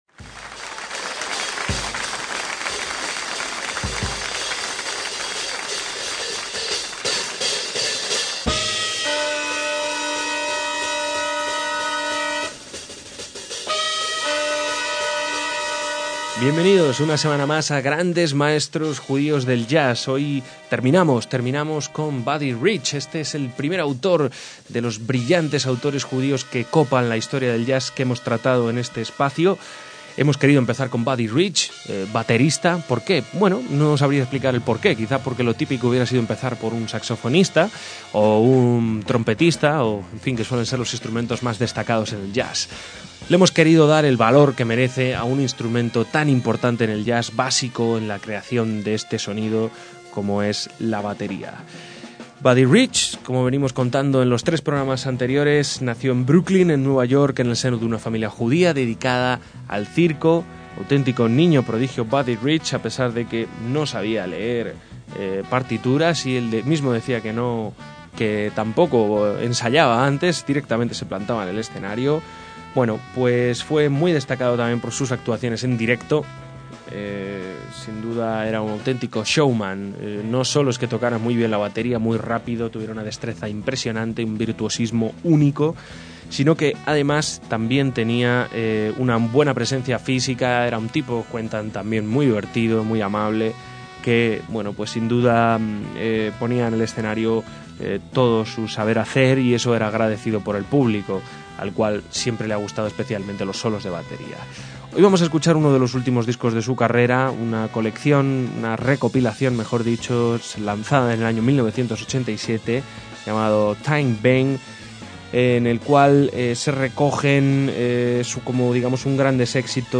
batería de jazz